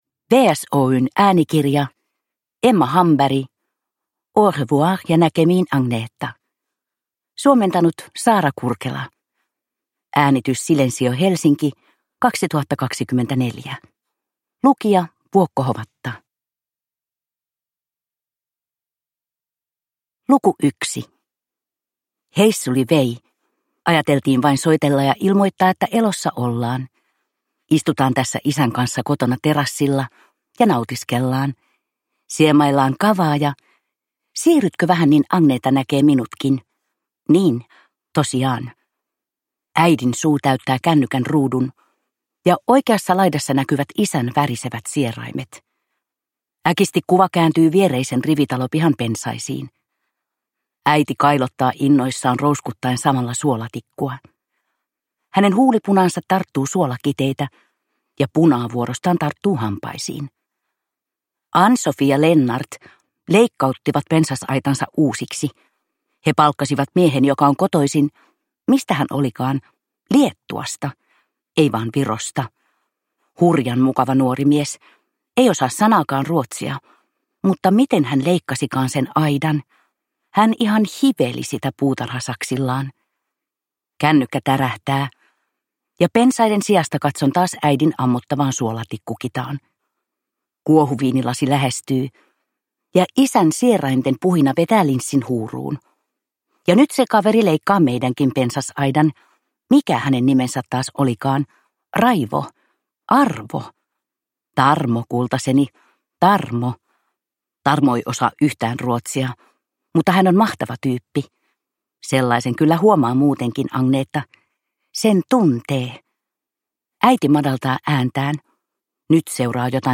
Au revoir ja näkemiin, Agneta – Ljudbok